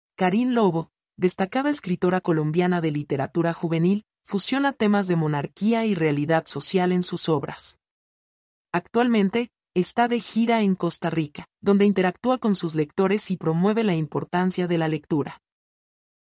mp3-output-ttsfreedotcom-54-1-1.mp3